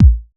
• Low Tone Bass Drum Single Shot A# Key 510.wav
Royality free kick drum sample tuned to the A# note. Loudest frequency: 108Hz
low-tone-bass-drum-single-shot-a-sharp-key-510-mW5.wav